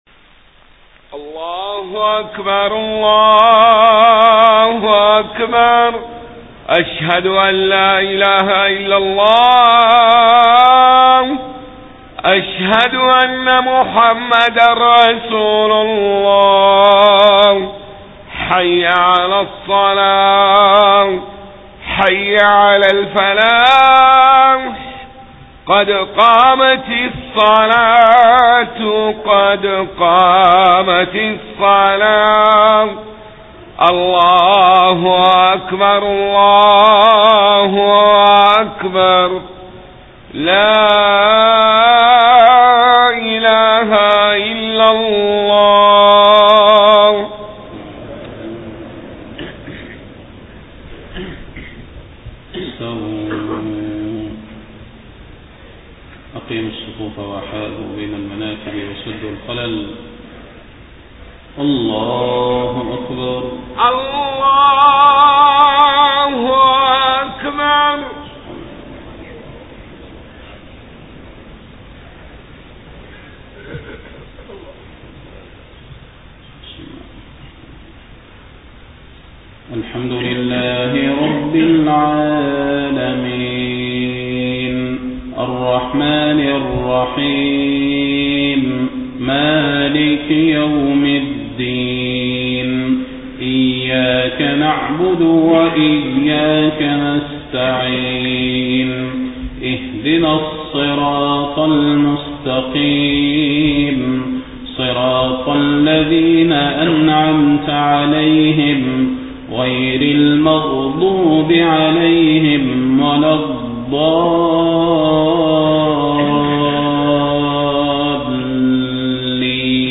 صلاة الفجر 6 ربيع الأول 1431هـ فواتح سورة غافر 1-20 > 1431 🕌 > الفروض - تلاوات الحرمين